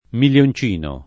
vai all'elenco alfabetico delle voci ingrandisci il carattere 100% rimpicciolisci il carattere stampa invia tramite posta elettronica codividi su Facebook milioncino [ mil L on ©& no ] s. m. — non millioncino né miglioncino : cfr. milione